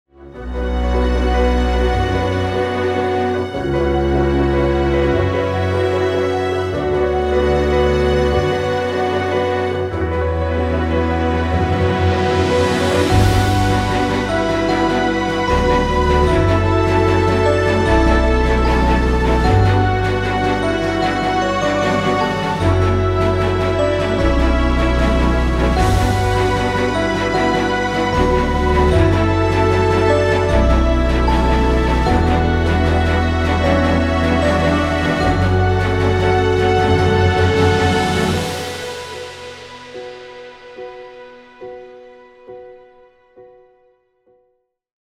Orchestral Theme